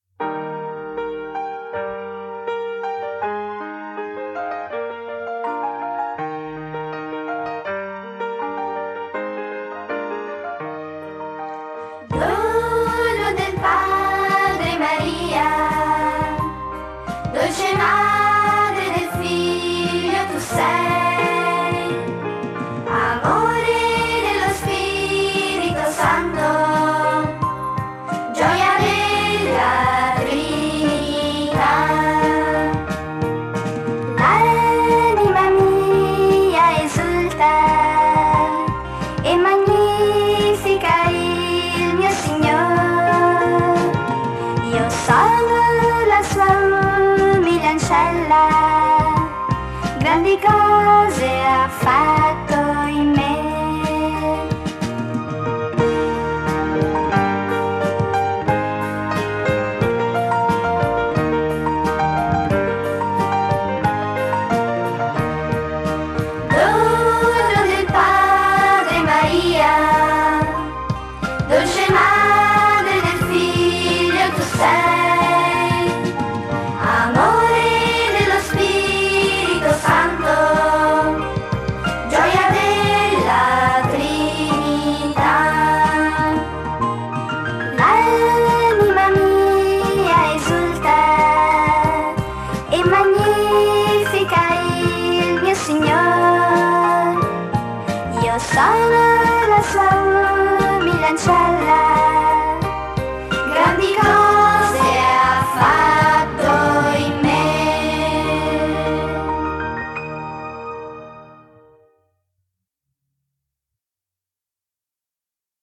Solennità della Santissima Trinità in Cenacolo Gam.
Canto per la Decina di Rosario e Parola di Dio: Dono del Padre, Maria